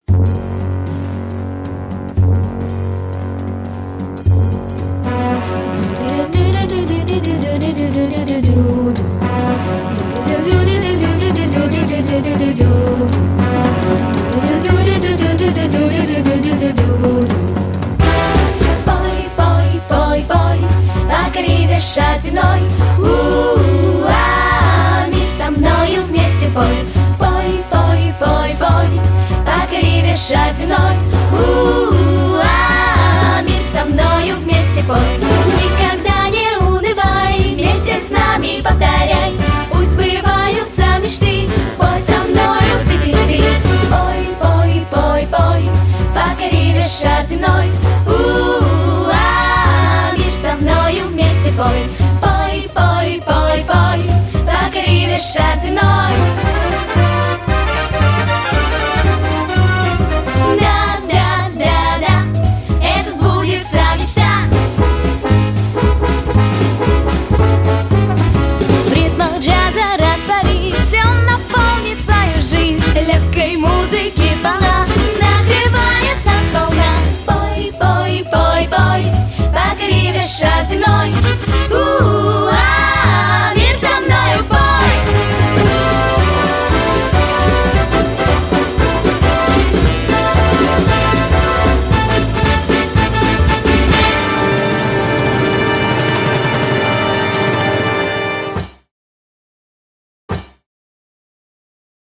• Категория: Детские песни
джаз, пение